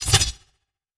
Whoosh Blade 001.wav